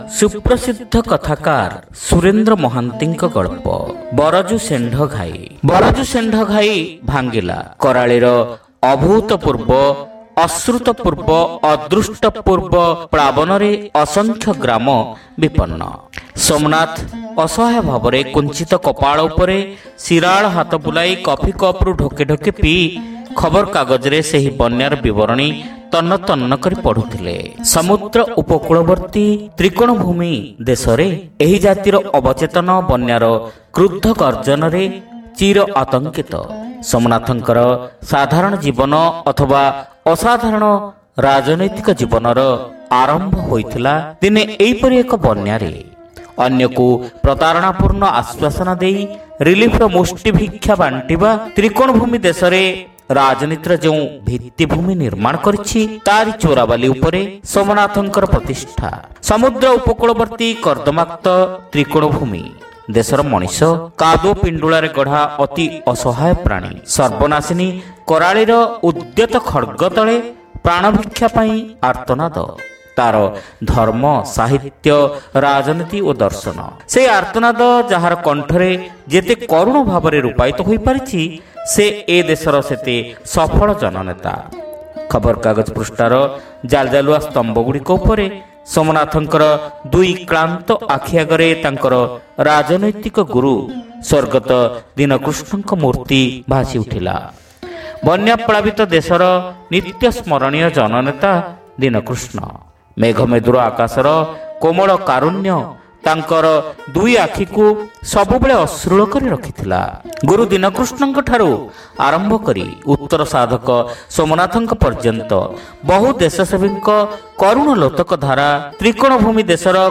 Audio Story : Baraju Sendha Ghai (Part-1)